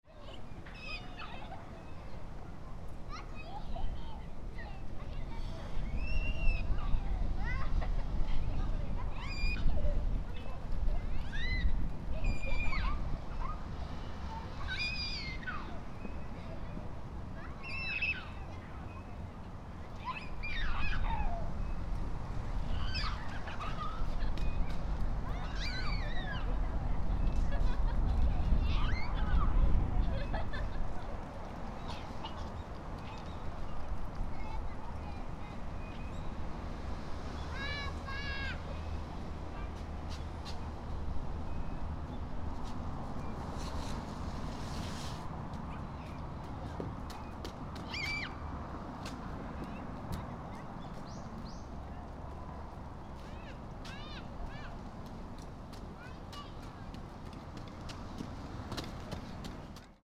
Fukushima Soundscape: Mt. Shinobu
Several children with their parents were playing in the park. ♦ Some crows were cawing, and some birds were twittering.